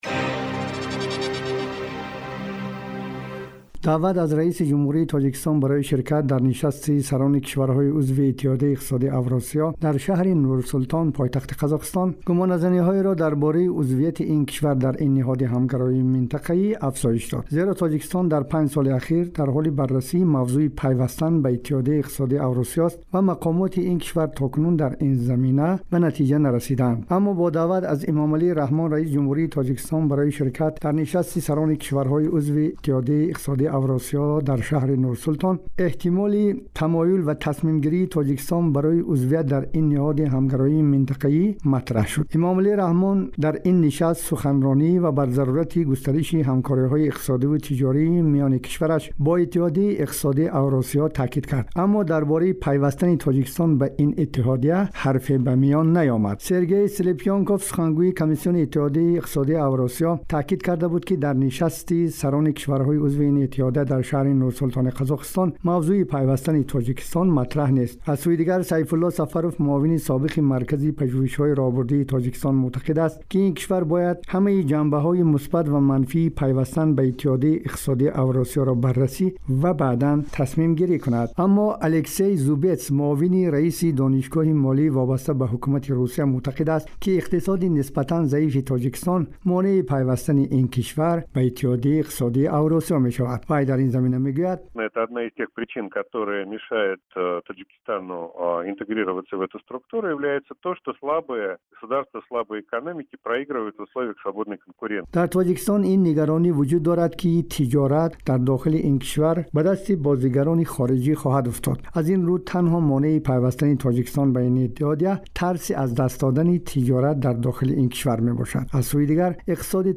Узвияти Тоҷикистон дар Иттиҳоди иқтисодии АвруОсиё дар печухами тасмимгириҳо. гузориши вижа